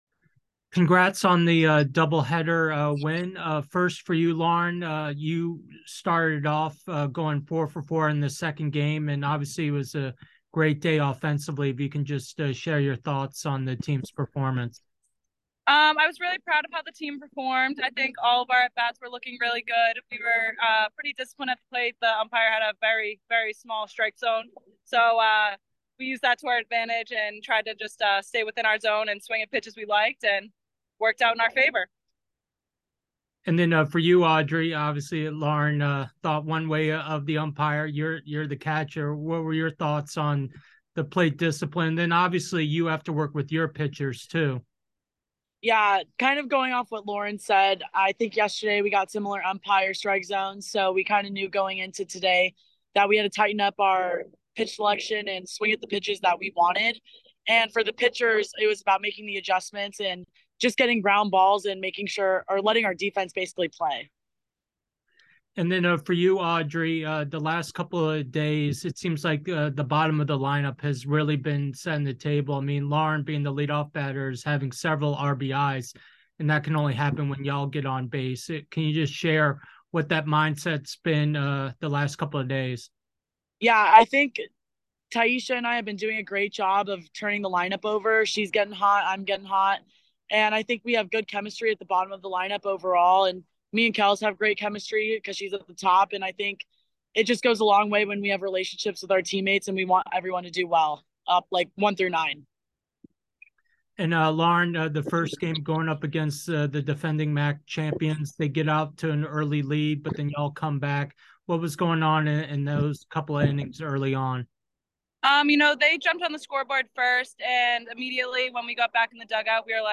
Cougar Classic Day 2 Postgame Interview